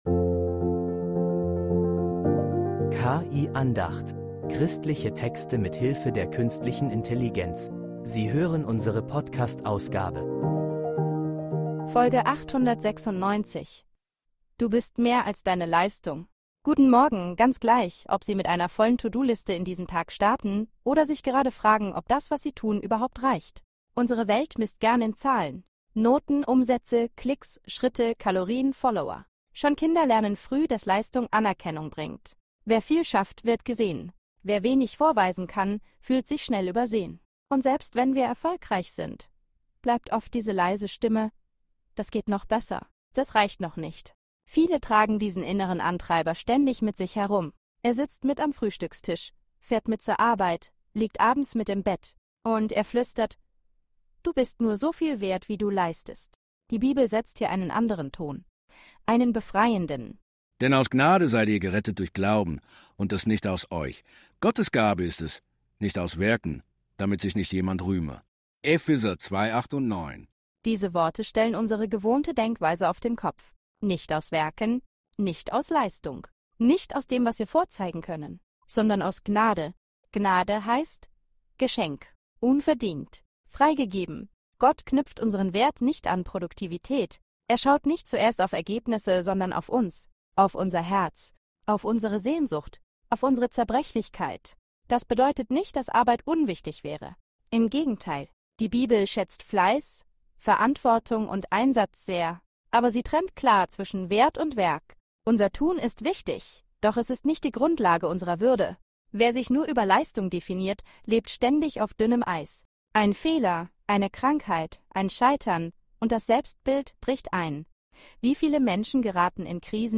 Eine Andacht über Freiheit vom inneren Leistungsdruck